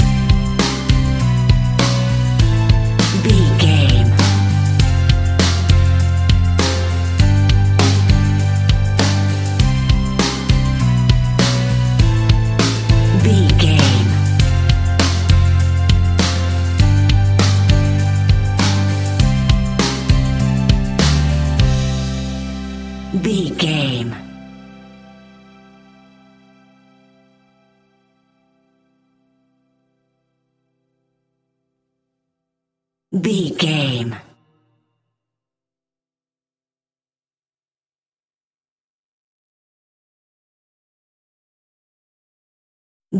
Ionian/Major
calm
happy
smooth
uplifting
electric guitar
bass guitar
drums
pop rock
indie pop
instrumentals